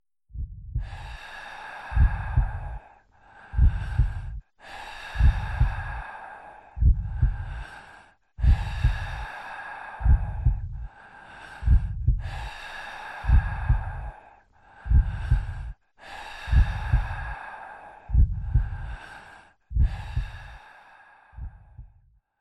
breath.mp3